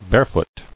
[bare·foot]